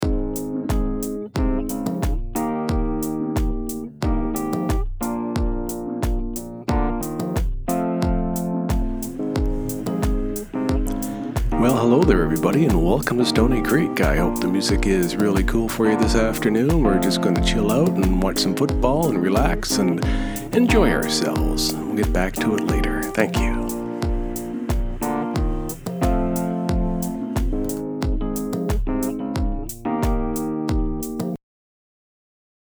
‘Jazzy Guy Radio Voice’ Sample Audio